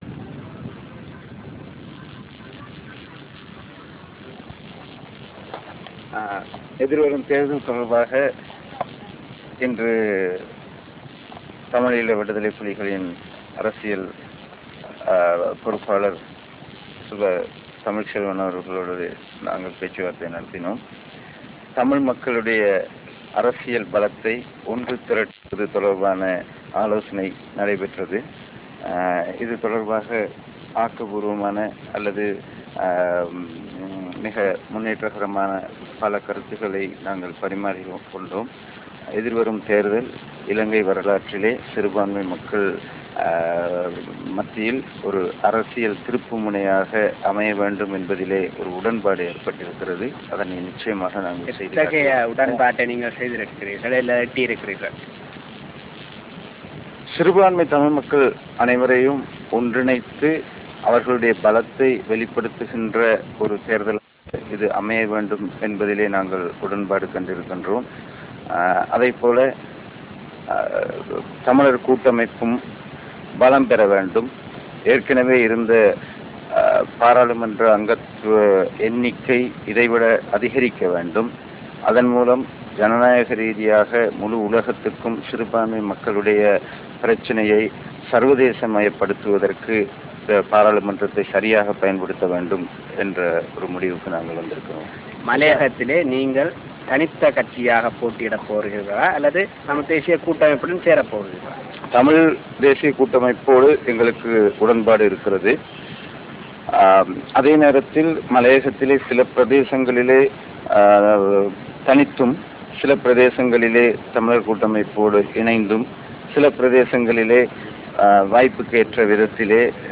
Real Audio Icon Interview with Chandrasekaran (Tamil)